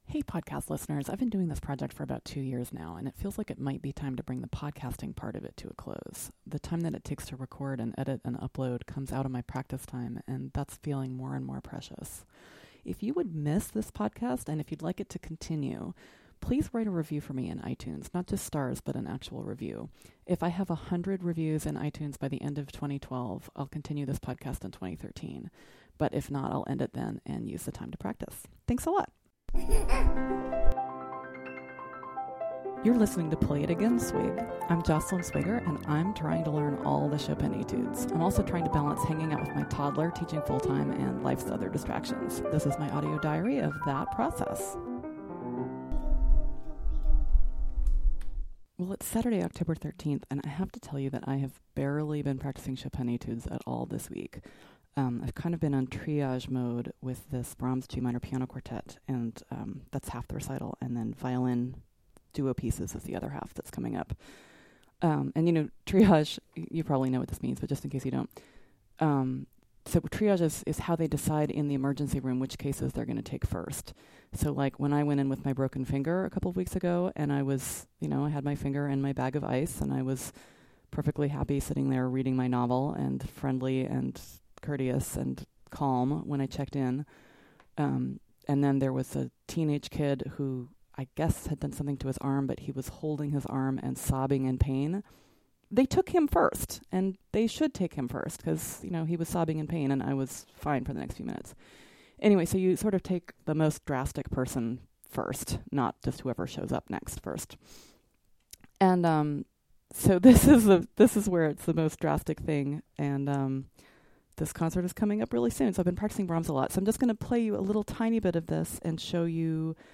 Sorry the piano audio is a little soft this time–I wasn’t careful when I set up the microphone.